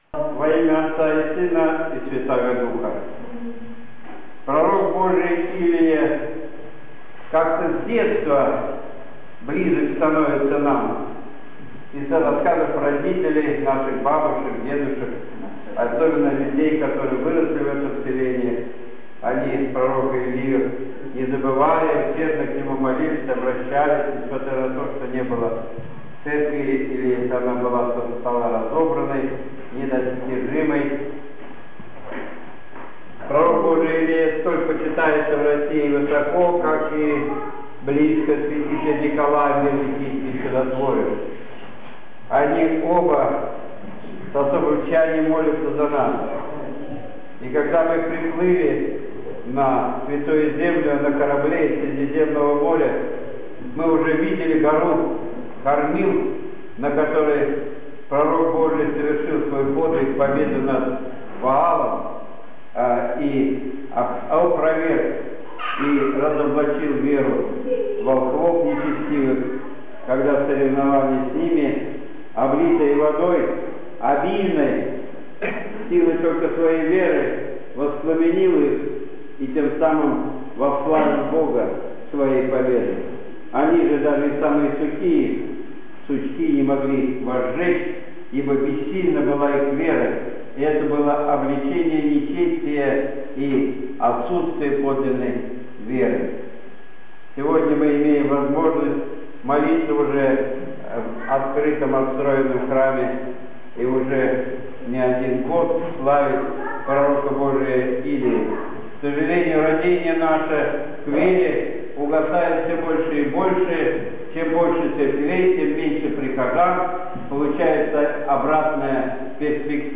Богослужение в Праздник Пророка Илии 2 августа 2013 года.